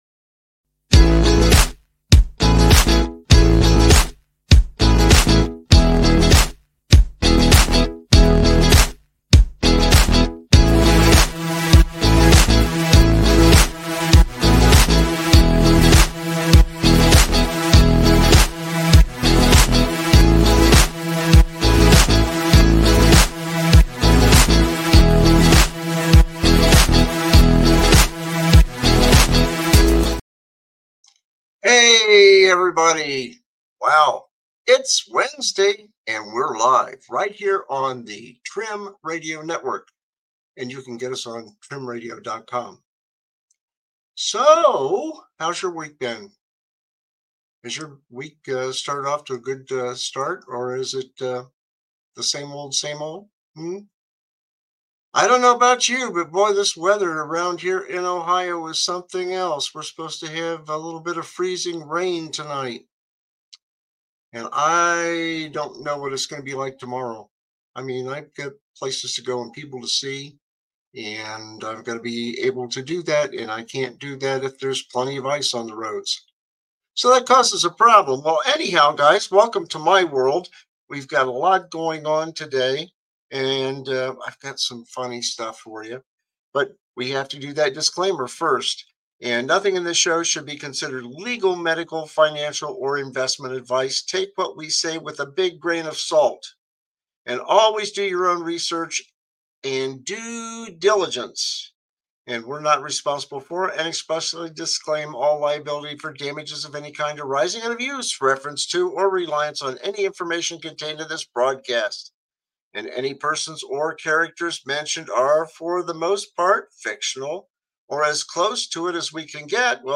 Expect plenty of laughter, thought-provoking discussions, and honest conversations.